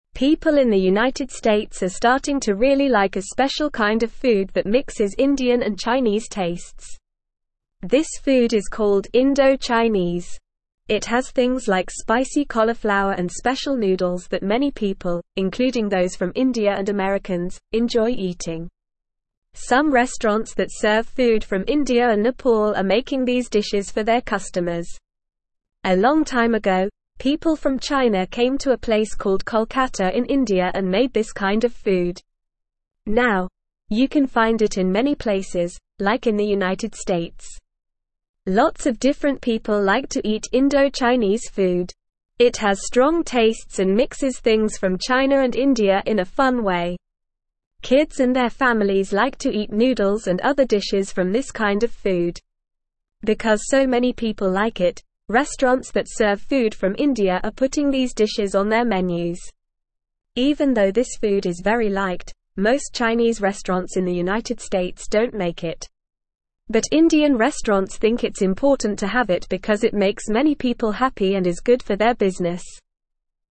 English-Newsroom-Beginner-NORMAL-Reading-Indo-Chinese-Food-A-Tasty-Mix-of-India-and-China.mp3